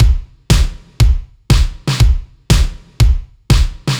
Index of /musicradar/french-house-chillout-samples/120bpm/Beats
FHC_BeatC_120-02_KickSnare.wav